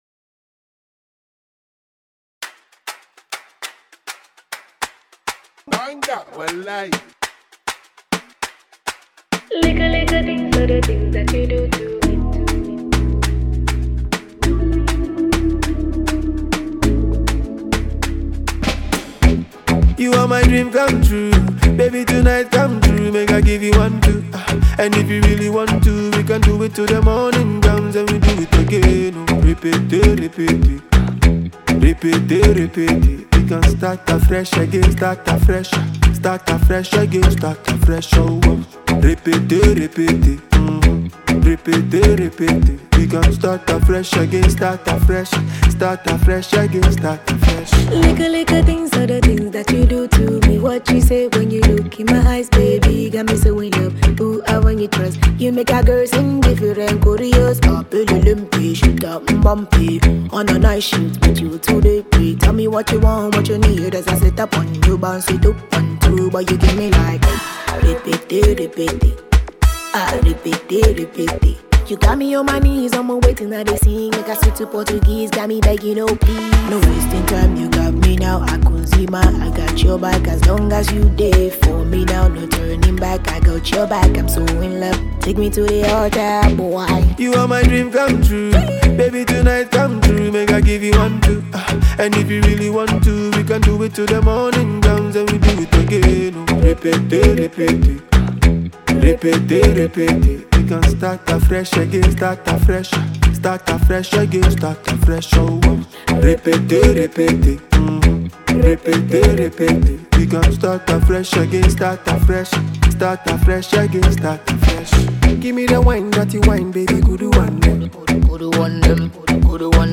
Ghana Afrobeat MP3